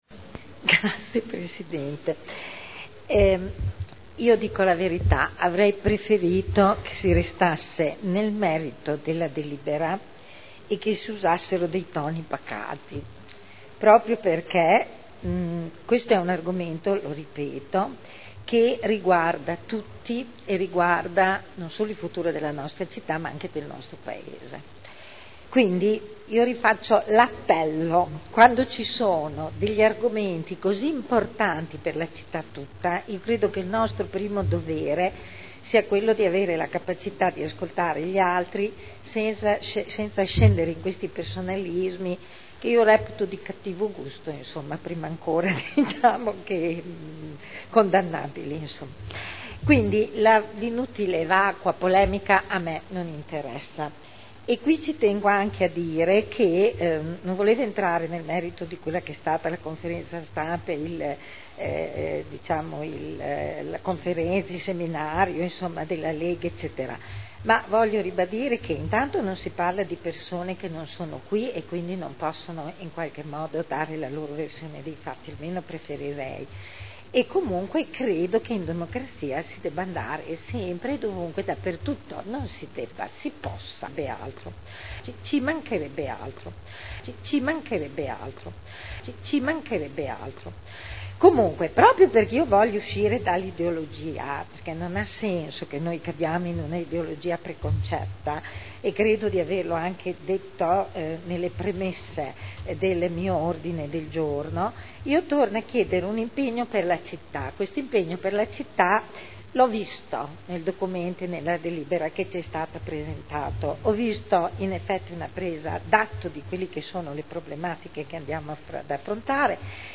Eugenia Rossi — Sito Audio Consiglio Comunale
Politiche per la sicurezza della città e dei cittadini: Linee di indirizzo Dichiarazioni di voto